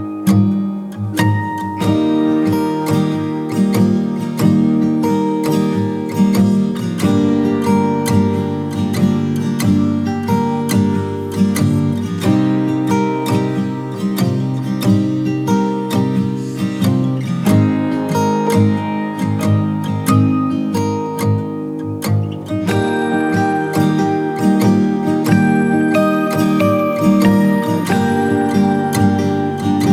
• TV Soundtrack